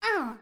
SFX_Mavka_Hit_Voice_02.wav